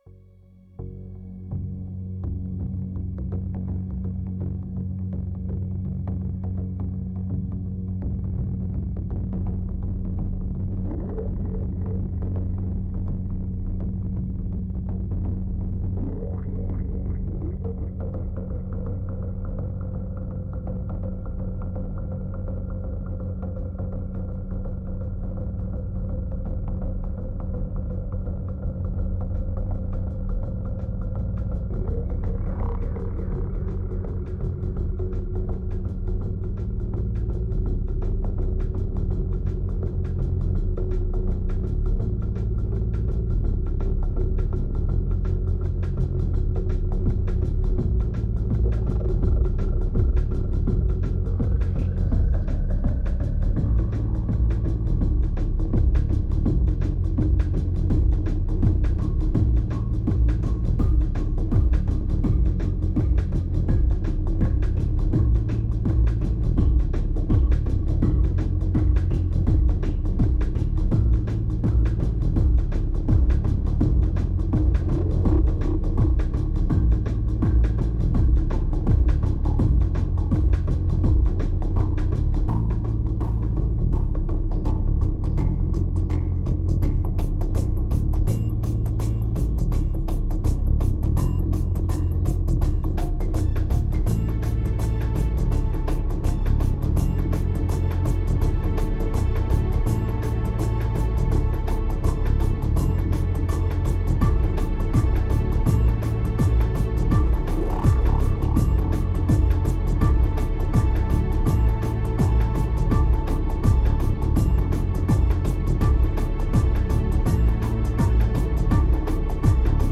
2324📈 - -9%🤔 - 83BPM🔊 - 2010-12-18📅 - -166🌟